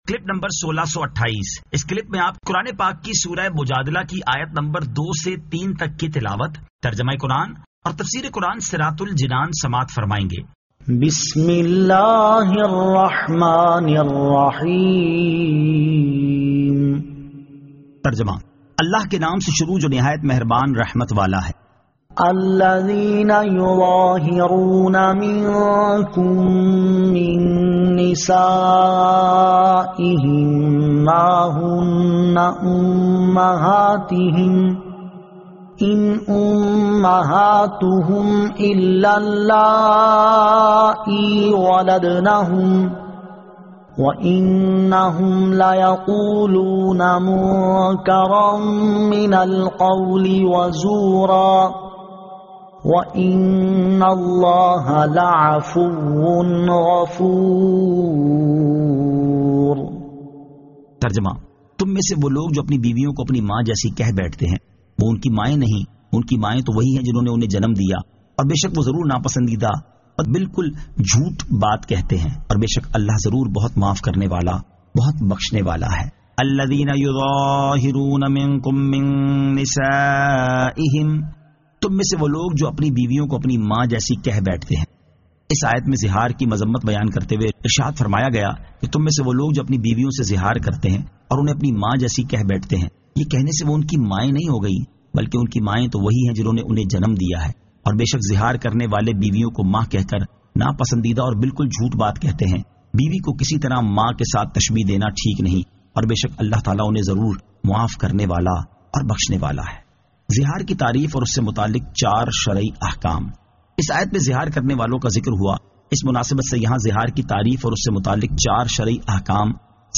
Surah Al-Mujadila 02 To 03 Tilawat , Tarjama , Tafseer